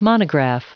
Prononciation du mot : monograph